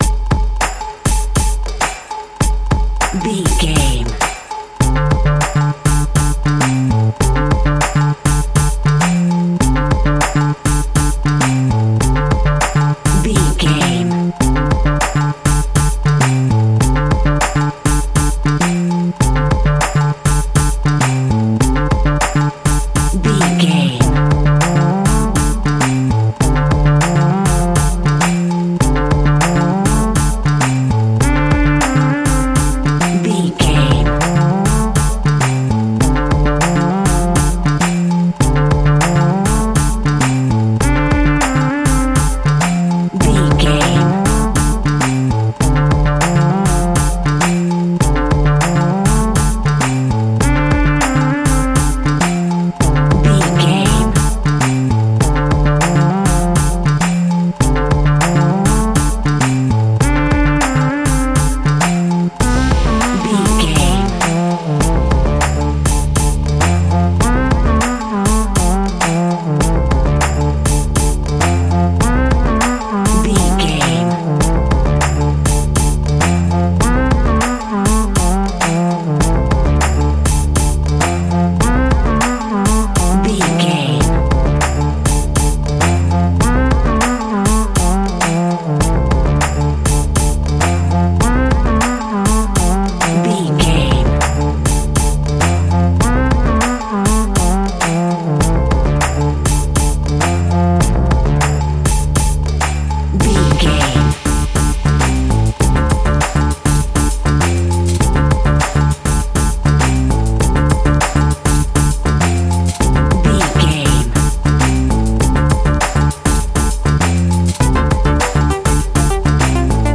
Aeolian/Minor
D
hip hop
hip hop instrumentals
downtempo
synth lead
synth bass
synth drums
turntables